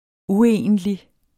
Udtale [ ˈuˌejˀəndli ]